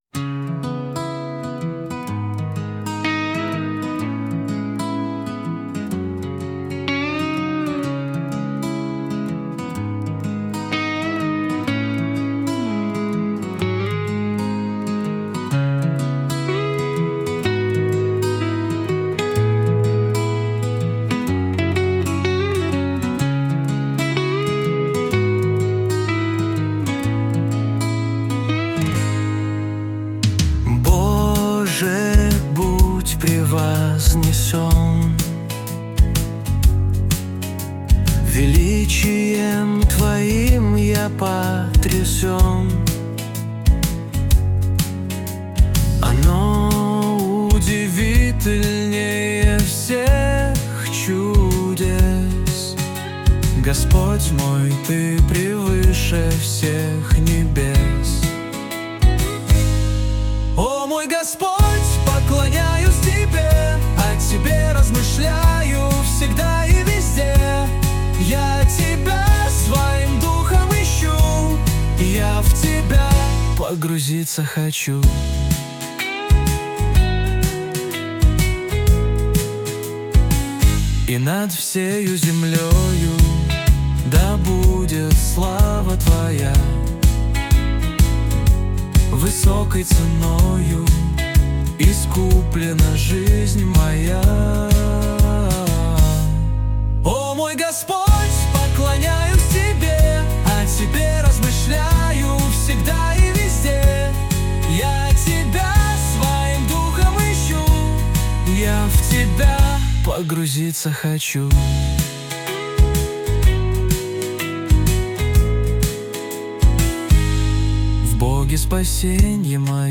песня ai
139 просмотров 1009 прослушиваний 50 скачиваний BPM: 125